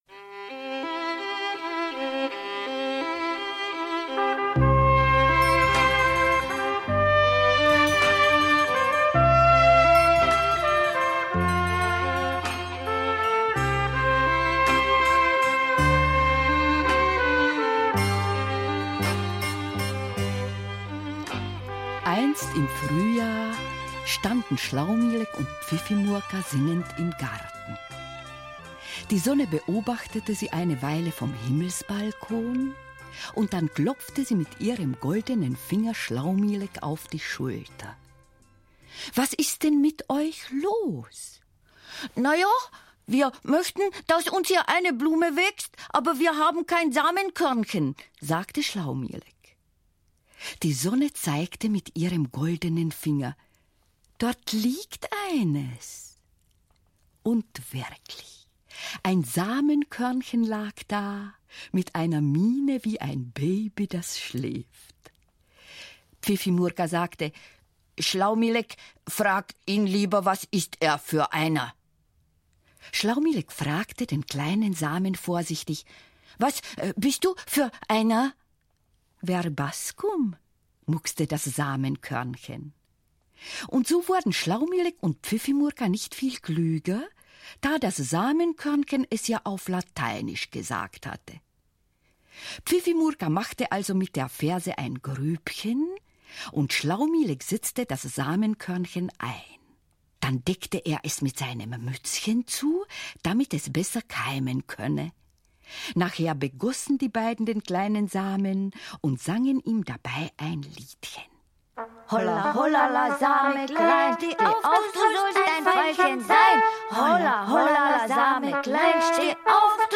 Die wunderbaren Abenteuer von Schlaumilek und Pfiffimurka audiokniha